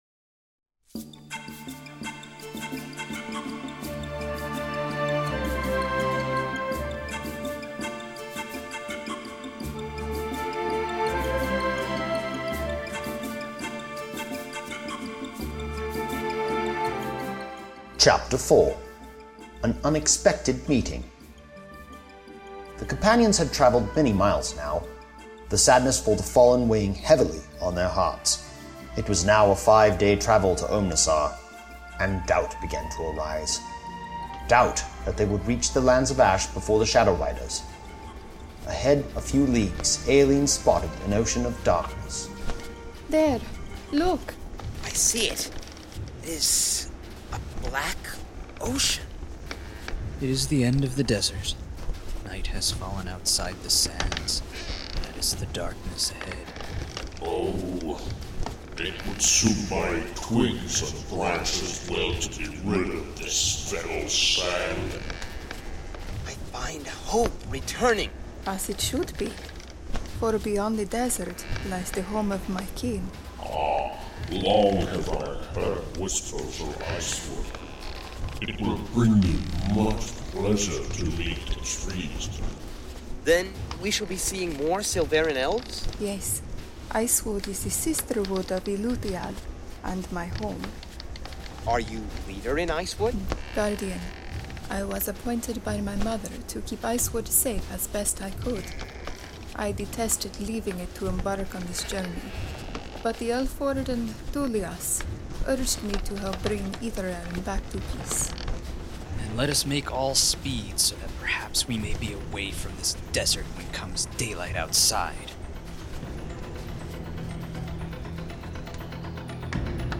I play the Silveren Elf Éelene Shadowleaf, a sorceress.